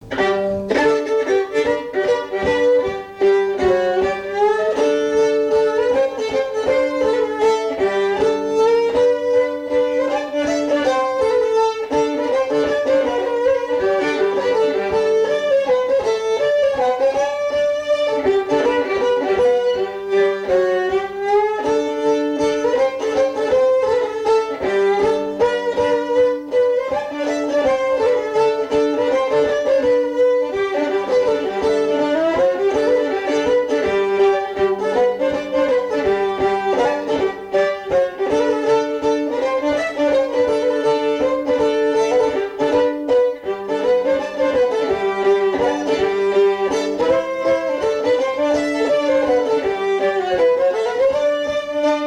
danse : java
Pièce musicale inédite